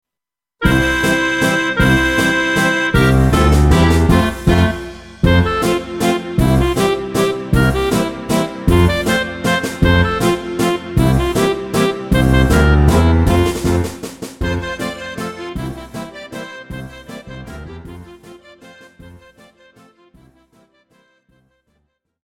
KARAOKE/FORMÁT:
Žánr: Folk